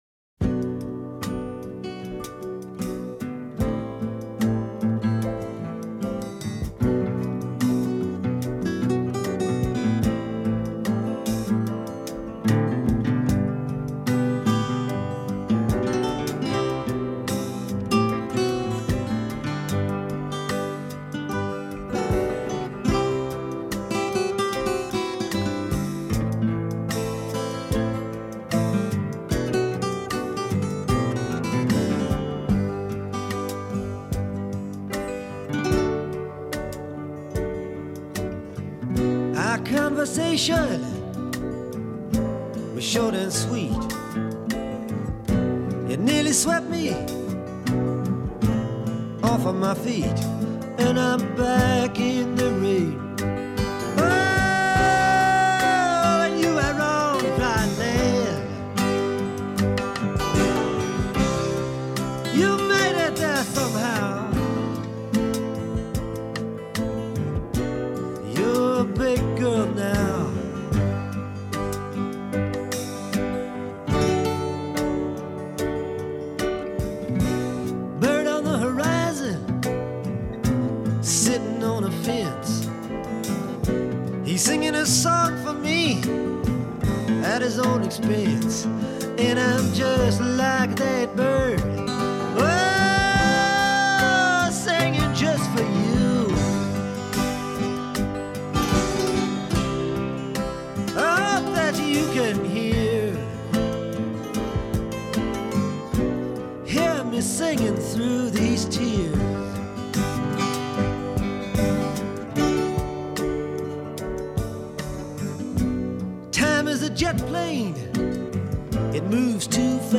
It seems to be slightly and purposely off key.